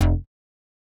TS Synth Bass_1.wav